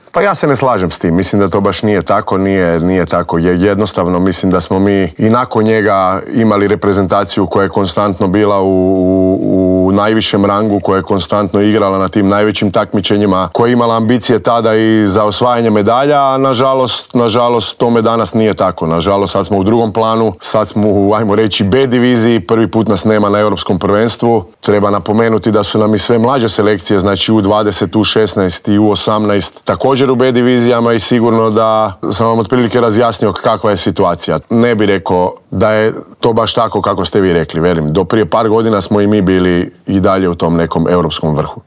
Iz godine u godinu se nadamo da će nam taj naš sport krenuti uzlaznom putanjom, a na čelo kao novi sportski direktor Hrvatskog košarkaškog saveza došao je tek umirovljeni igrač, Krunoslav Simon, koji je bio gost Intervjua tjedna Media servisa.